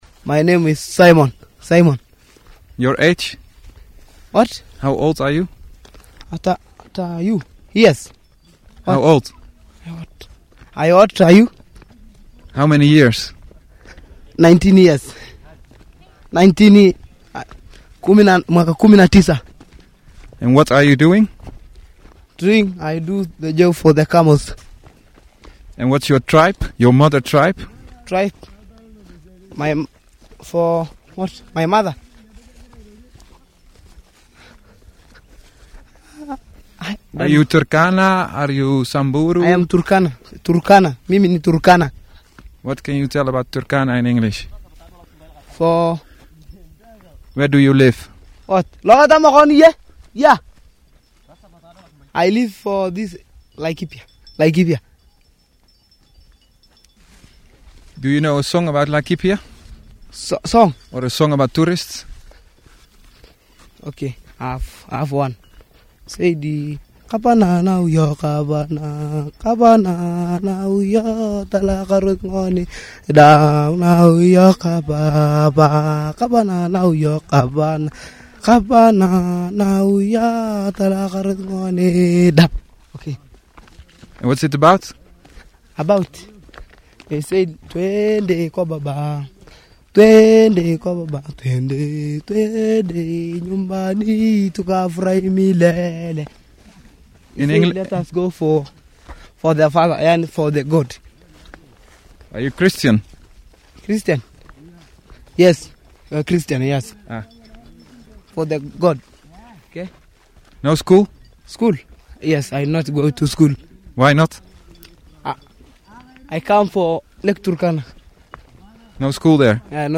That day it was sunny, hot and we were walking through teh semi desert area with… camels. He sings and I tried to have a conversation with him.